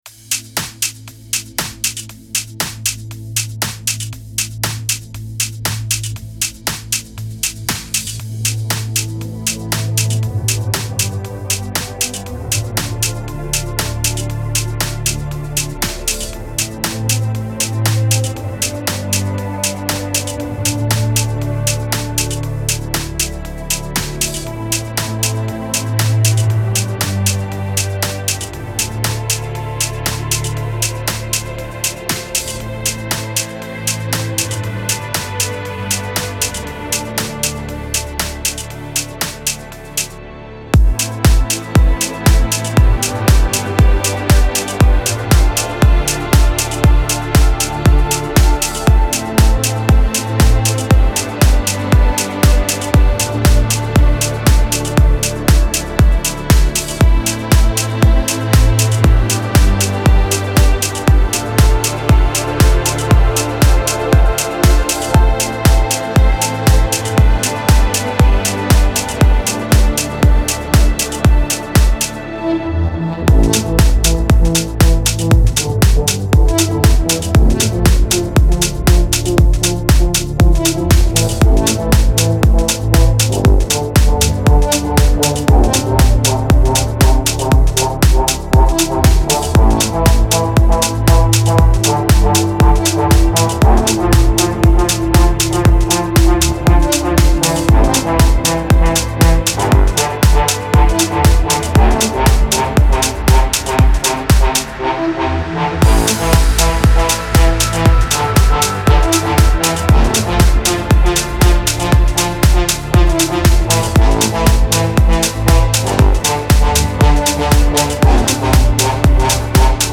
Genre : Deep House